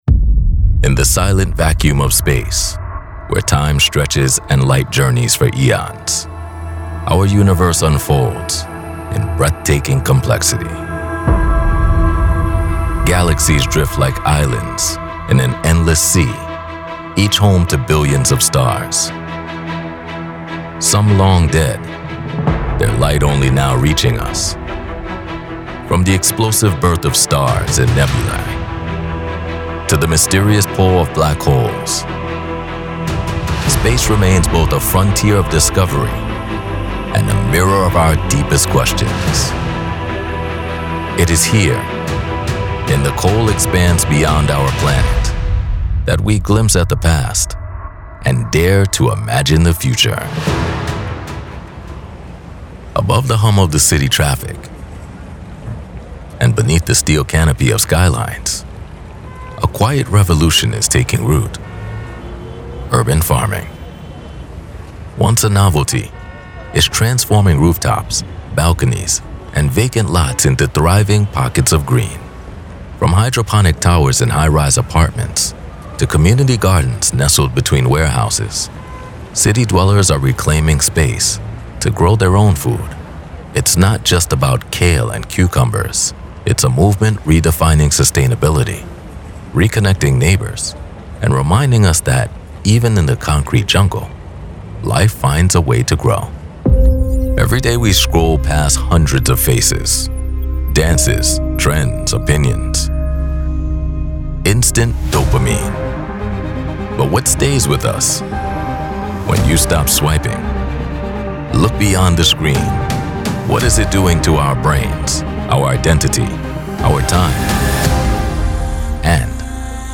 Documentary Voiceover Demo